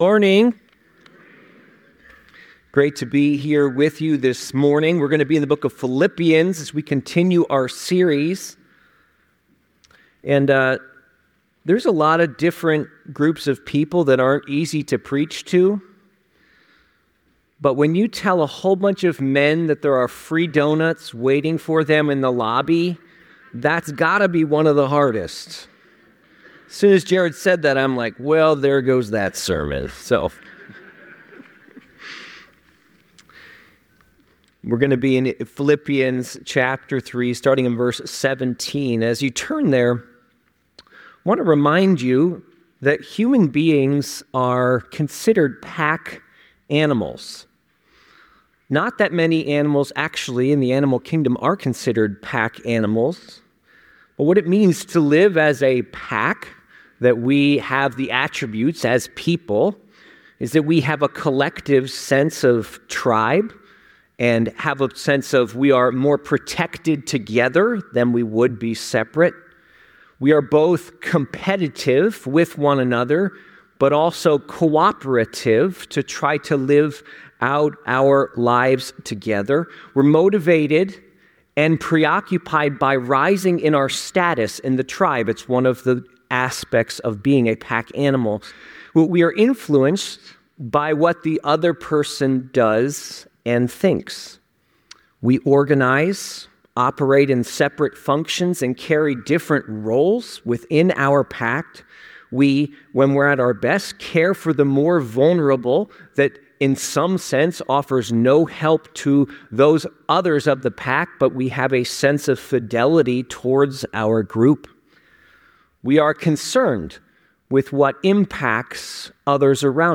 The sermon concludes with a call to action to seek mentors and to also be willing to mentor others, reinforcing the message that mentorship allows individuals to experience the love and transformative power of Christ more fully.